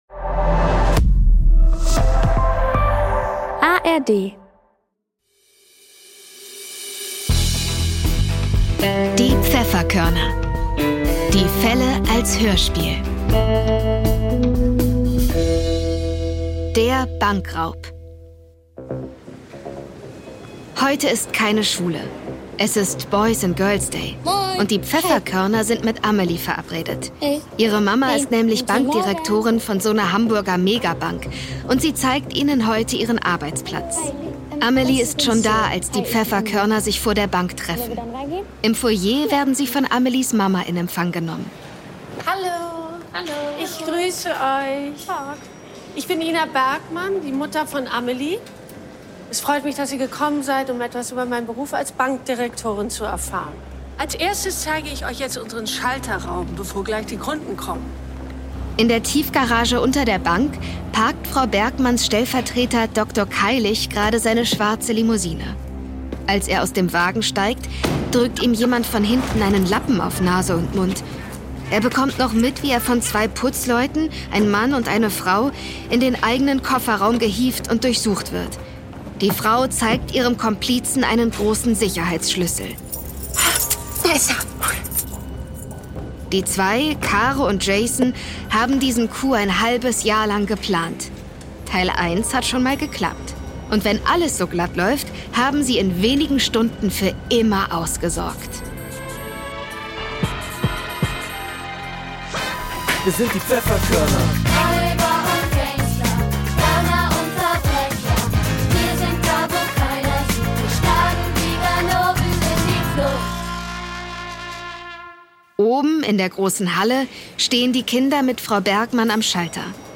Der Bankraub (11/21) ~ Die Pfefferkörner - Die Fälle als Hörspiel Podcast